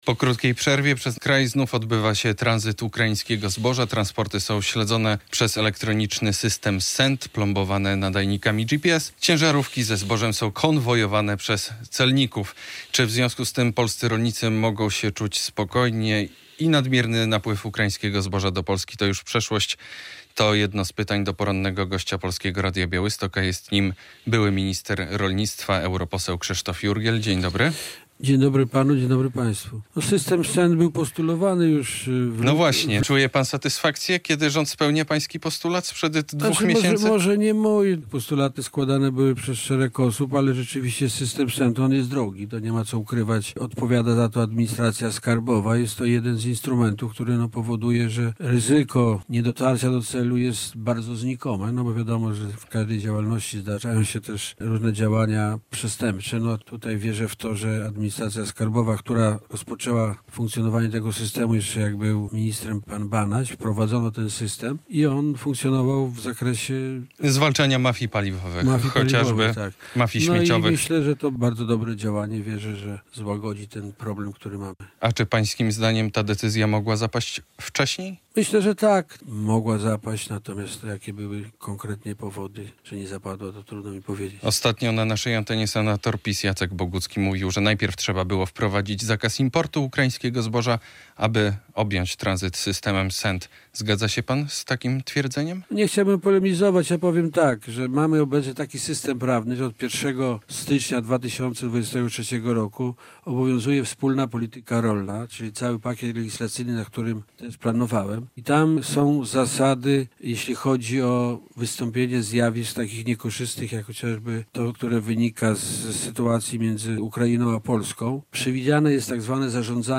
Między innymi o tym w rozmowie z byłym ministrem rolnictwa, podlaskim europosłem Krzysztofem Jurgielem.